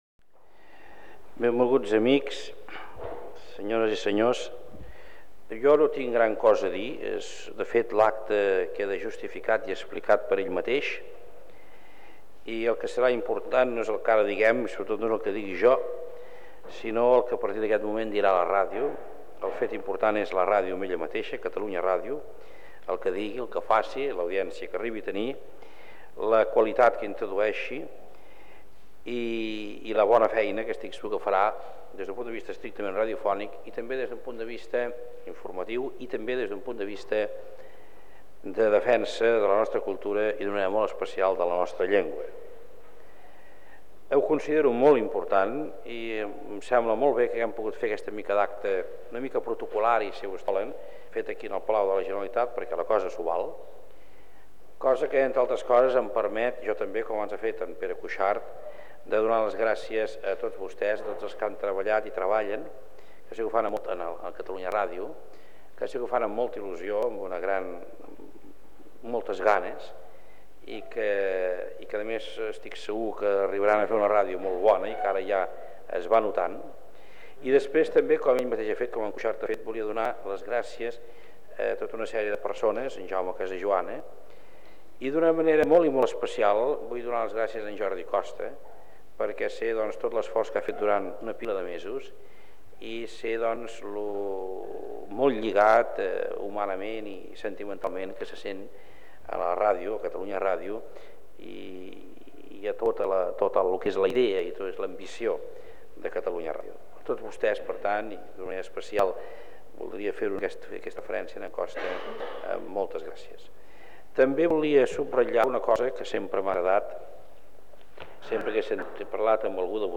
Paraules del president de la Generalitat de Catalunya Jordi Pujol.
Informatiu